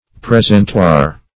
Search Result for " presentoir" : The Collaborative International Dictionary of English v.0.48: Presentoir \Pres`en*toir"\, n. [Formed after analogy of French.] An ornamental tray, dish, or the like, used as a salver.